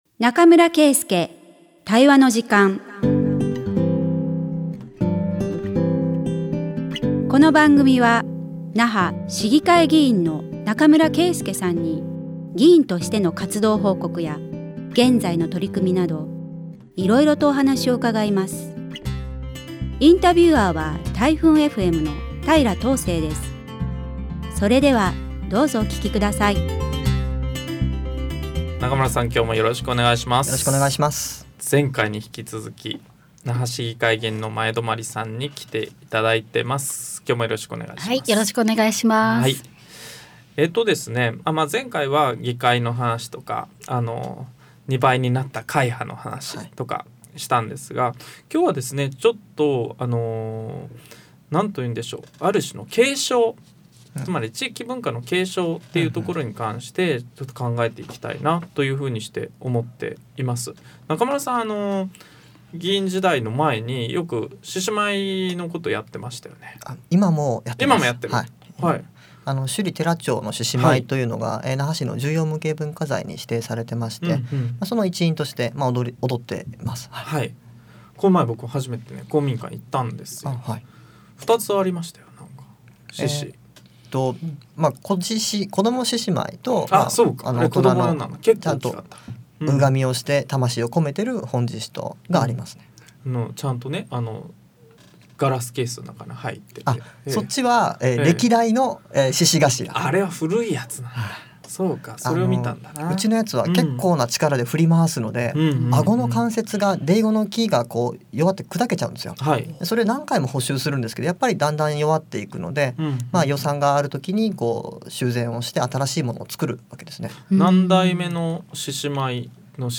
140516中村圭介対話の時間vol.07 那覇市議会議員中村圭介が議員活動や現在の取組みを語る20分